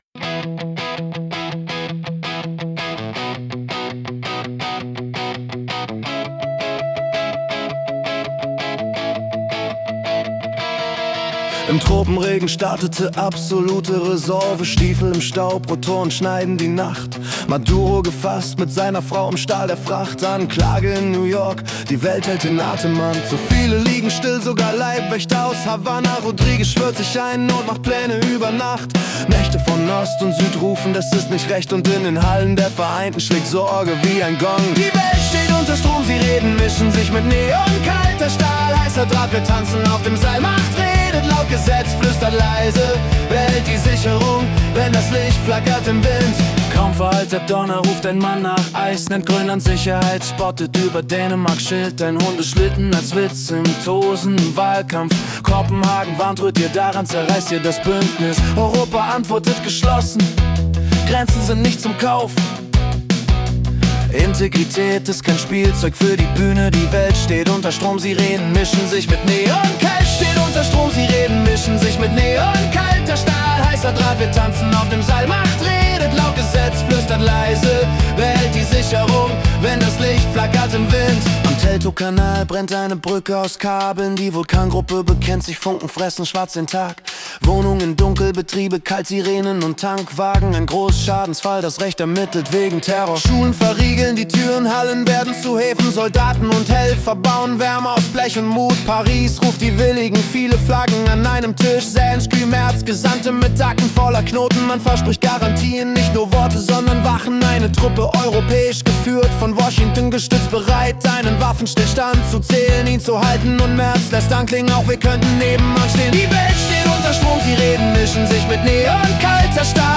Januar 2026 als Rock-Song interpretiert.